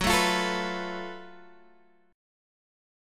F#mM7bb5 chord